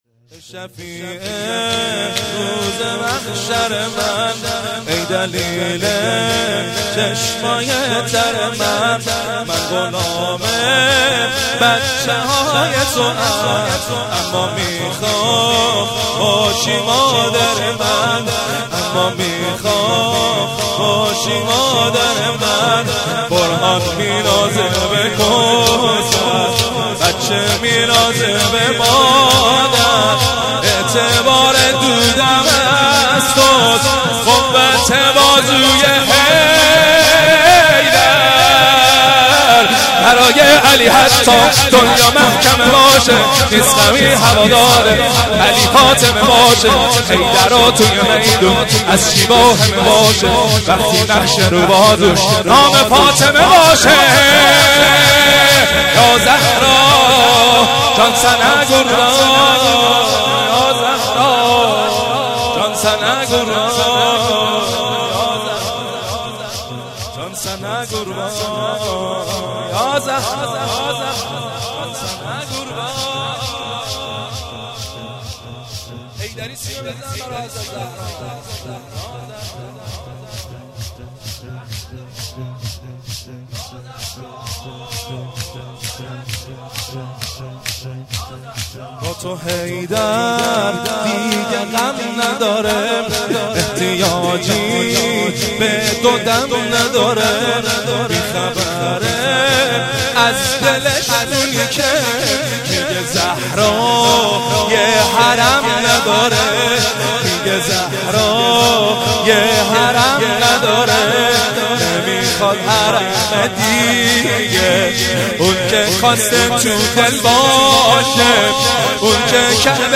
0 0 شور